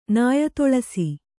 ♪ nāya toḷasi